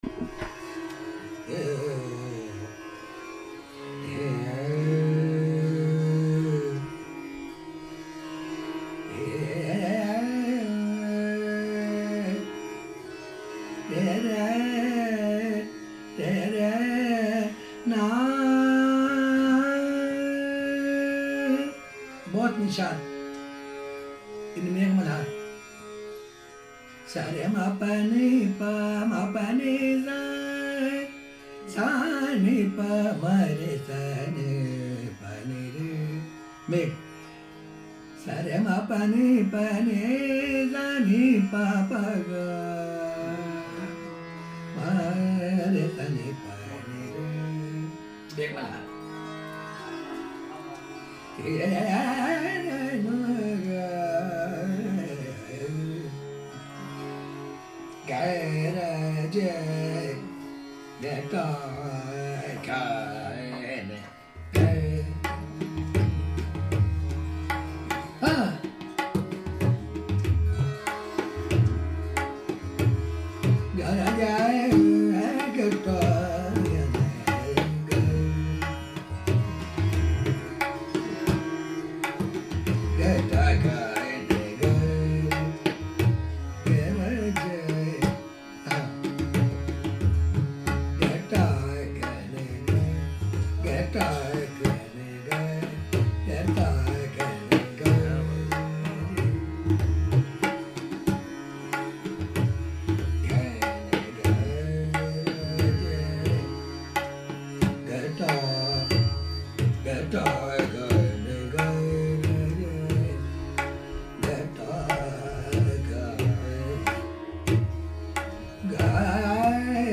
Ustad Iqbal Ahmed Khan On July 21, 2012we had the pleasure of hosting Ustad Iqbal Ahmed Khan Sahib, the Khalifa of the Delhi Khayal Gharana. The Baithak is presented here in its entirety Raga Jaunpuri Gaud Sarang Megh Malhar Soordasi Malhar & Gaud Malhar Miyan Ki Malhar Bhajan Soordasi Malhar